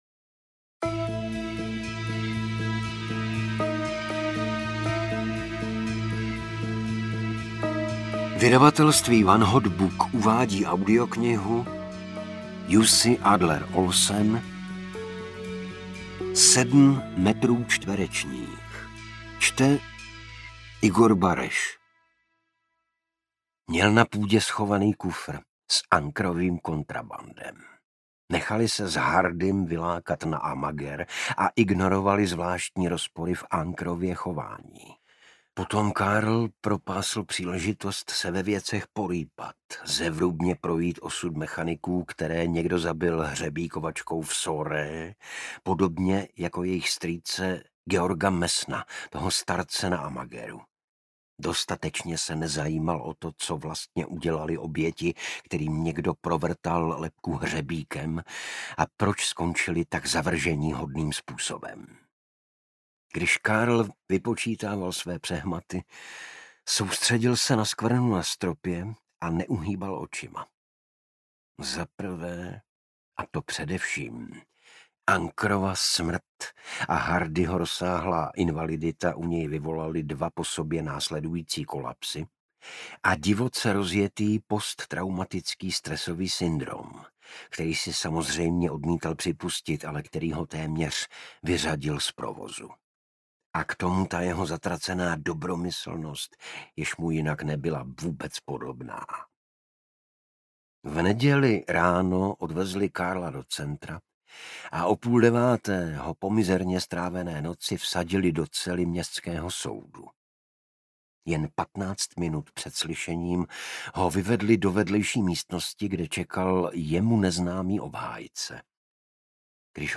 Sedm metrů čtverečních audiokniha
Ukázka z knihy